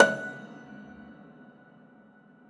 53o-pno16-F3.wav